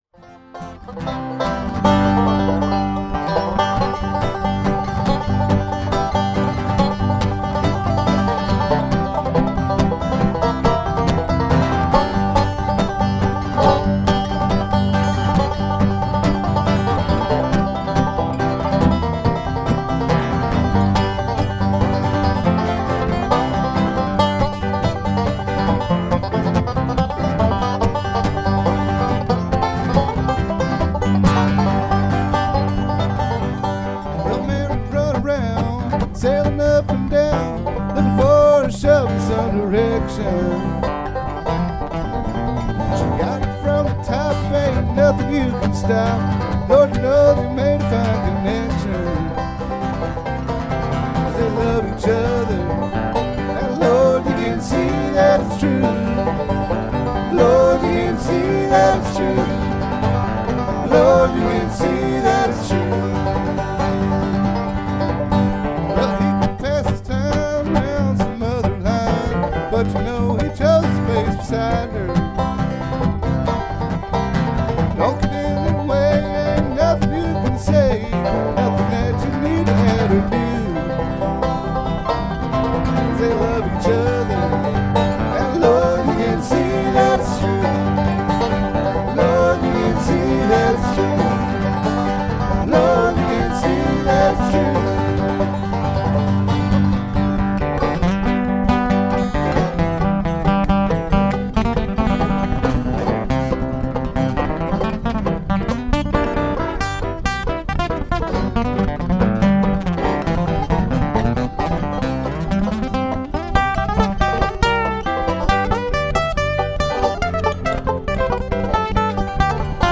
banjo, mandolin, fiddle & vocals
guitar & vocals
piano & vocals